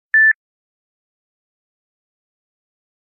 nokia-beep_24942.mp3